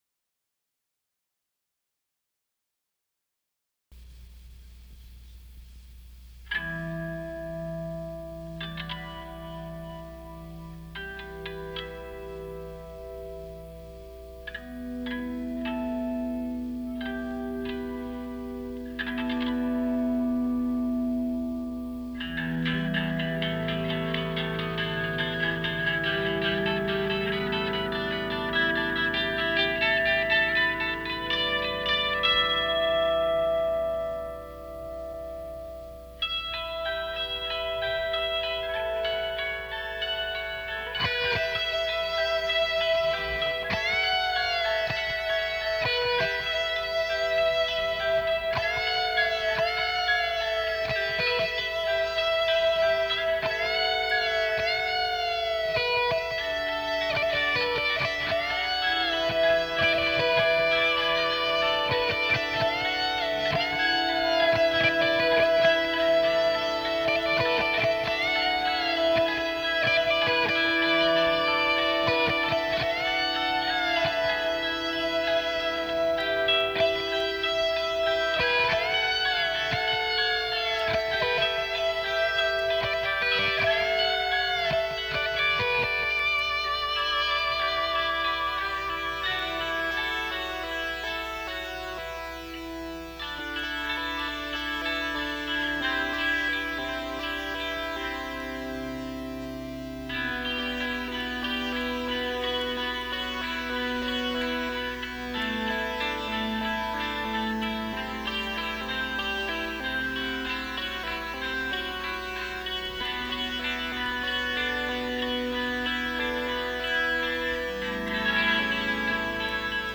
guitar and vocals
guitar
mellotron and synthesizer
drums
bass
I used my Gibson EDS 1275 double neck guitar for this intro.